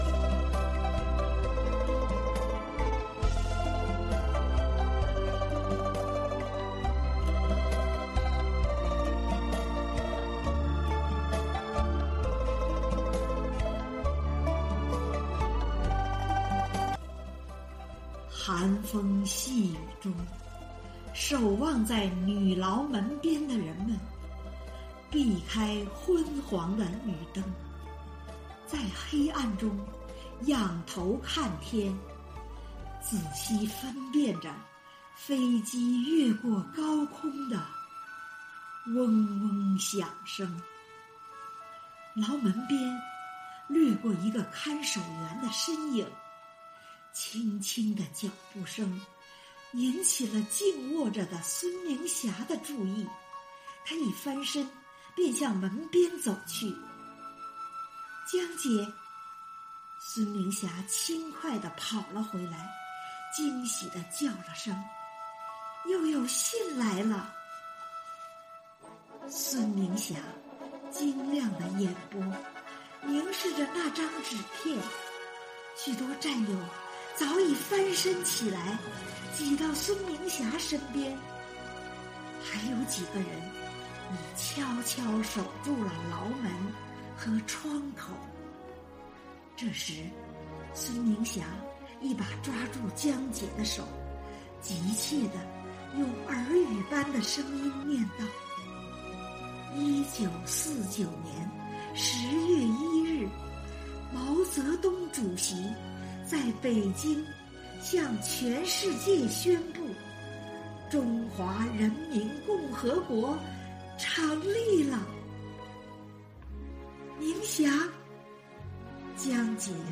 《红岩》节选独诵